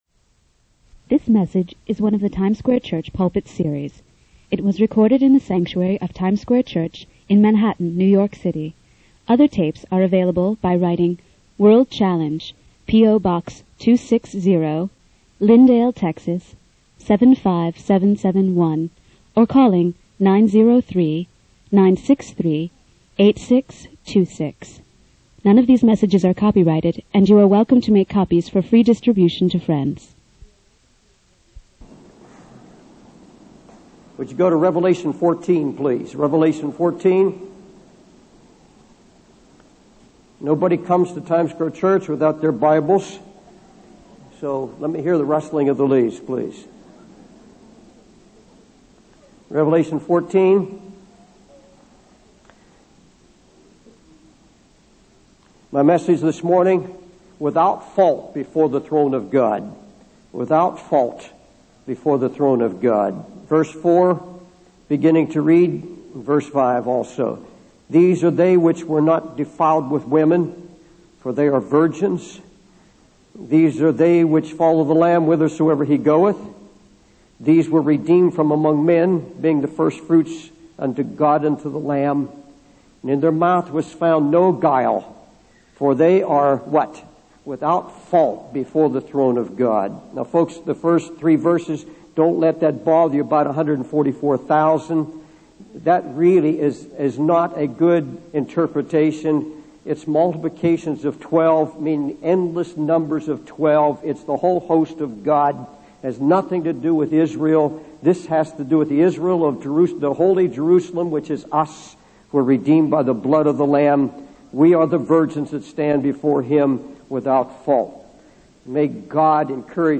In this sermon, Pastor Dave encourages the congregation to seek cleansing and purity in their hearts. He emphasizes the importance of being able to stand before God and give an account of every thought and deed.